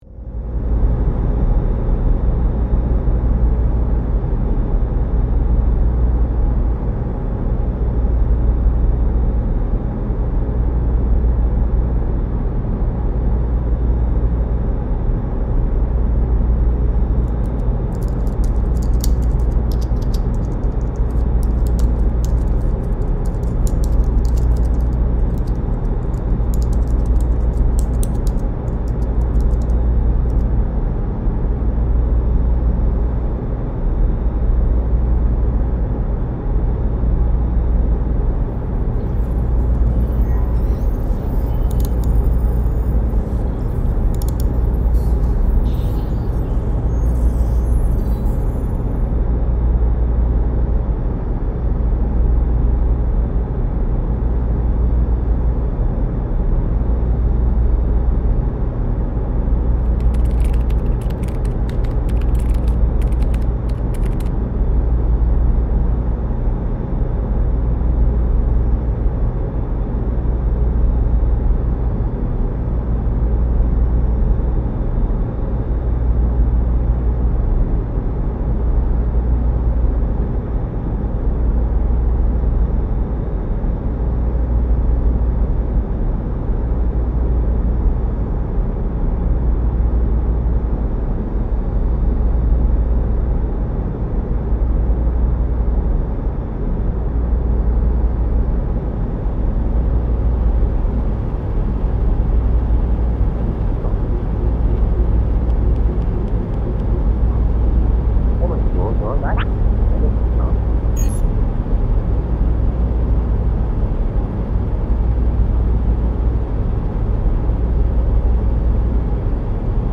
science lab scifi ambience 2 hours.mp3
science-lab-scifi-ambience-2-hours.mp3